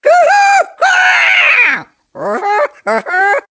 One of Diddy Kong's voice clips in Mario Kart Wii